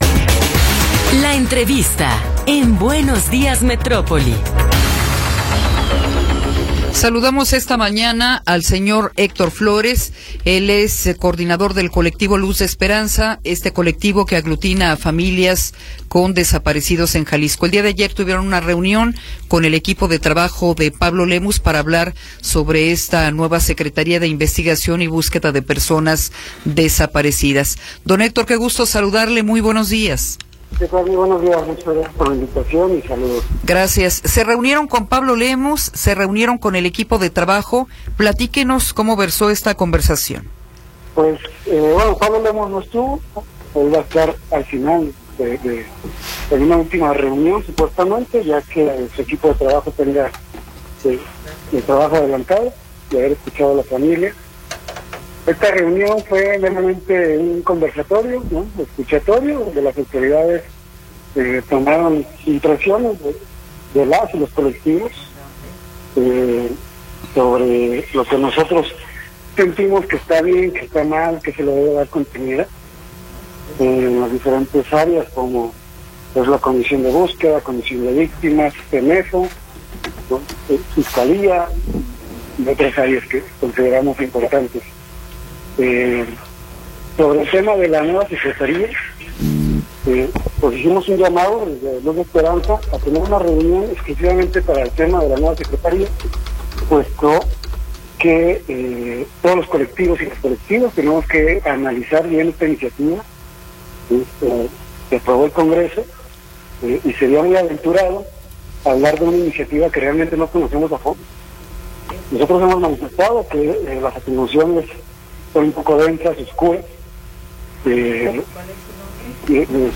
Entrevista
Entrevistas